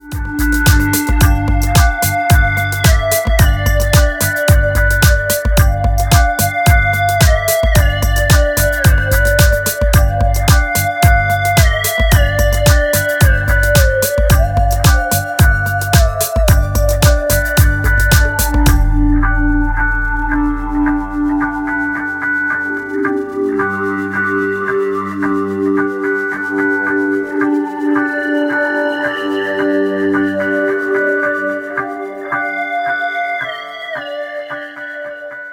На 6" мониторах не хватает вроде, хочется +3-4дБ подкинуть.
Но в конце 32-42Гц "электробочку" не слышно как хотелось бы слышать с таким "трясущим все вокруг" затуханием) Или это сам звук такой фиговый...